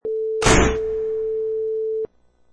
Sound: Small Metal Door Slams 2
Single quick metal door slam
Product Info: 48k 24bit Stereo
Category: Doors / Metal Doors
Relevant for: shut, metal, loud, bang, locker, room, small.
Try preview above (pink tone added for copyright).
Small_Metal_Door_Slams_2.mp3